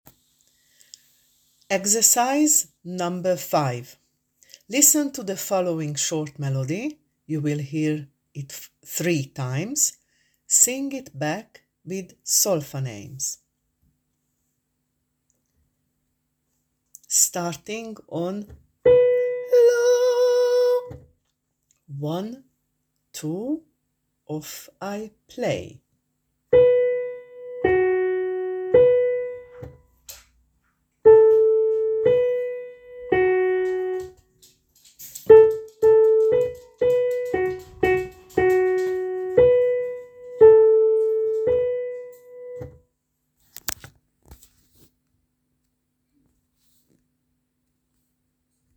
5.Listen to the following short melody (3 times). Sing it back with solfa names: